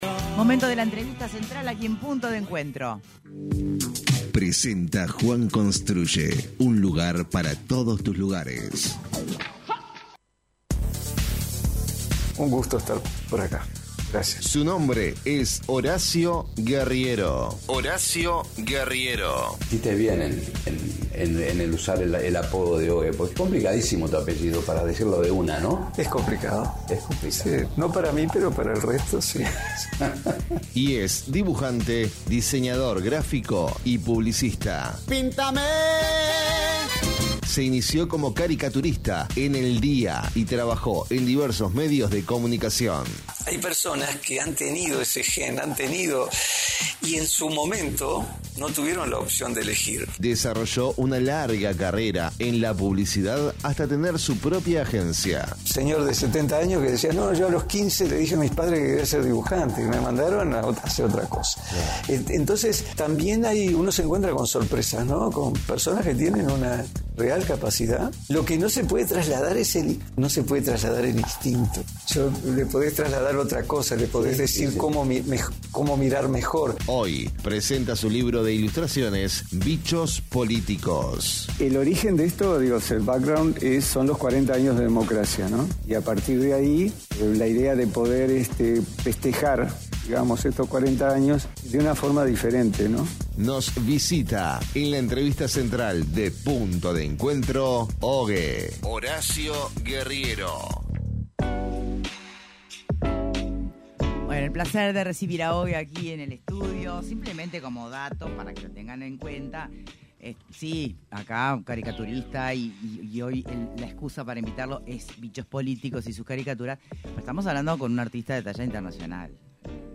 En entrevista con Punto de Encuentro